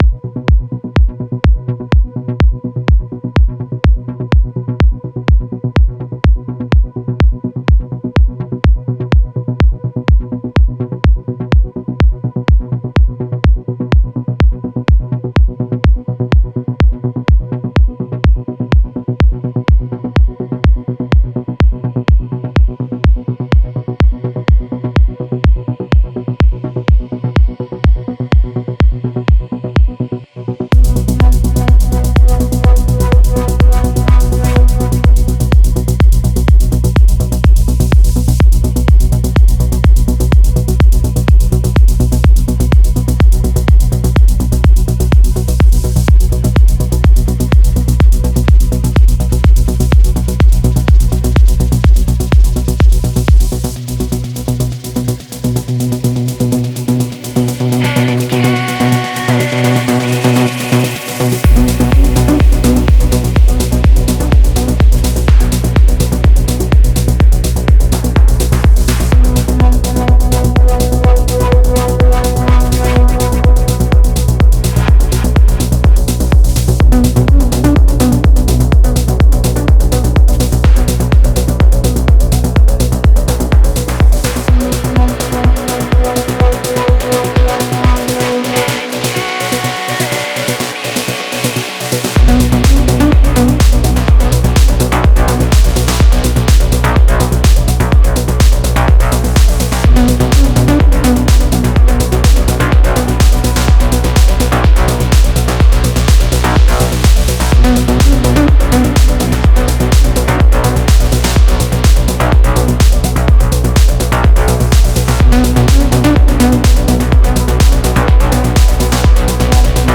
• Жанр: Techno